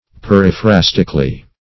Periphrastically \Per`i*phras"tic*al*ly\, adv.
periphrastically.mp3